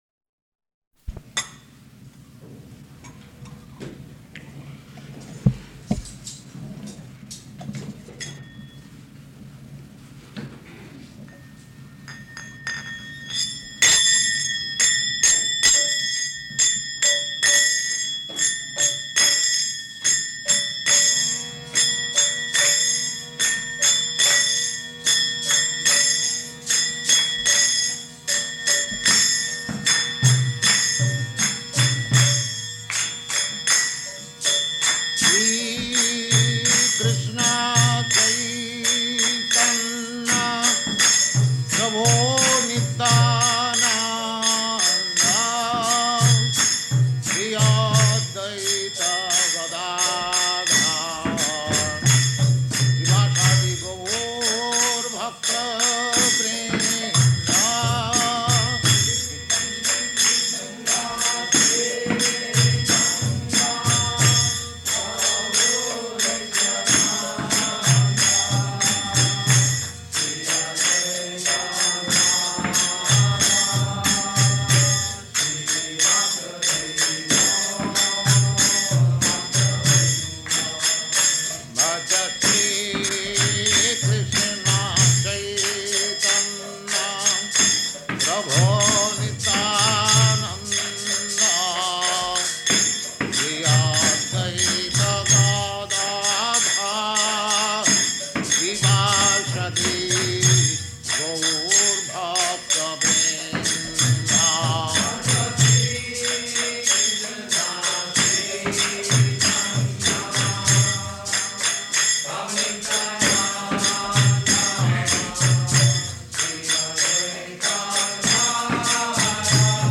Lecture to College Students
Lecture to College Students --:-- --:-- Type: Lectures and Addresses Dated: October 21st 1968 Location: Seattle Audio file: 681021LE-SEATTLE.mp3 Prabhupāda: [ kīrtana ] [ prema-dhvanī ] Thank you very much.